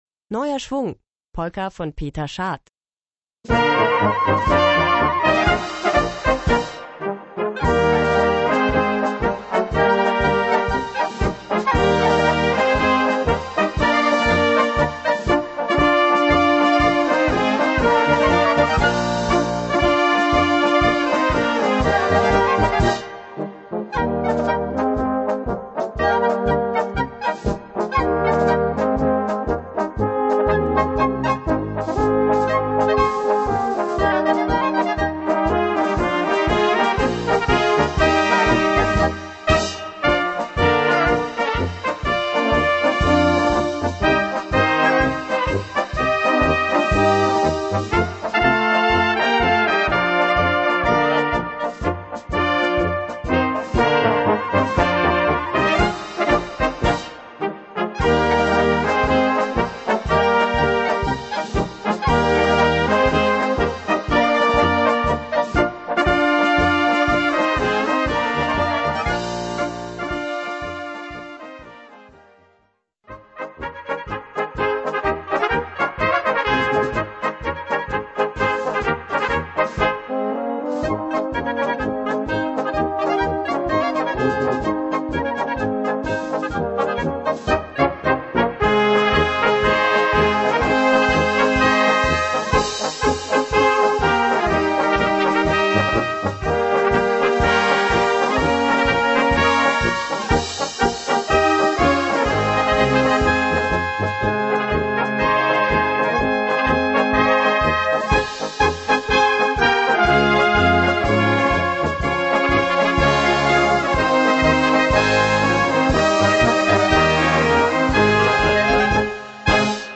Gattung: Volkstümliche Polka
Besetzung: Blasorchester